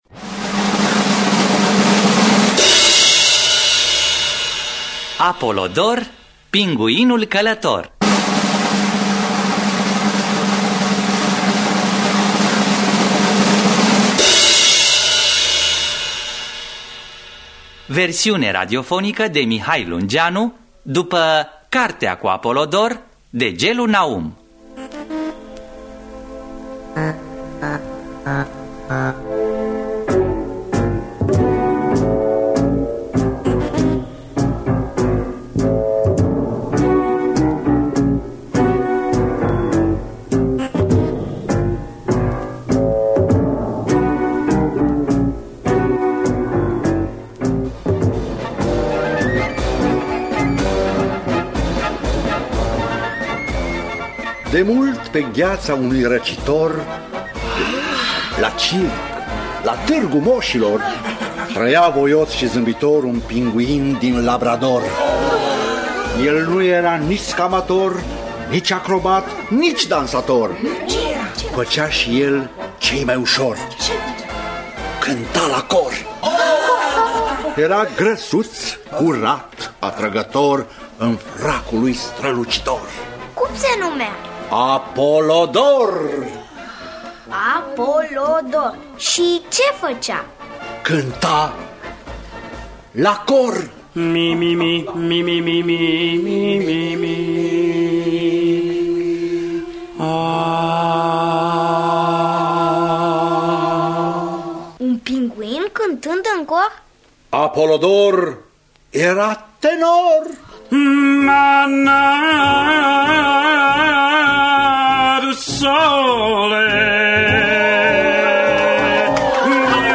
Apolodor, pinguinul călător de Gellu Naum – Teatru Radiofonic Online